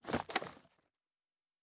pgs/Assets/Audio/Fantasy Interface Sounds/Book 01.wav at master